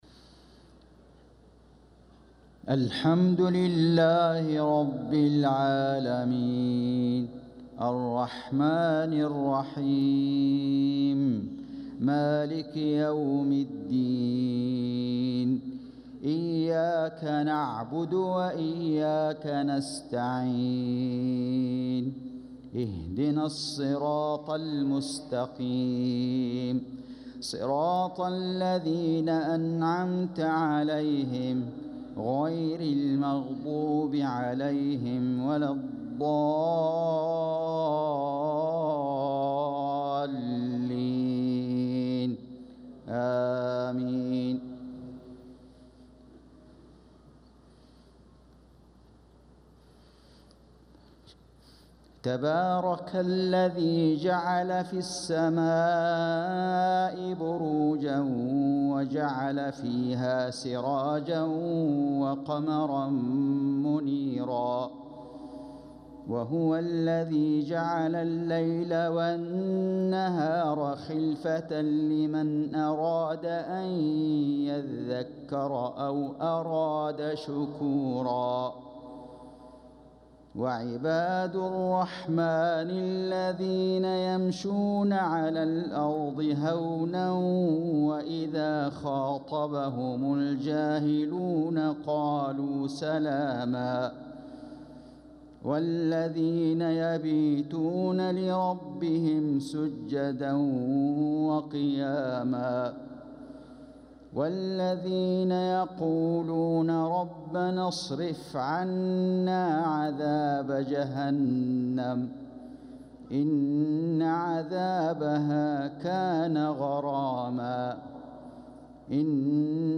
صلاة العشاء للقارئ فيصل غزاوي 9 صفر 1446 هـ
تِلَاوَات الْحَرَمَيْن .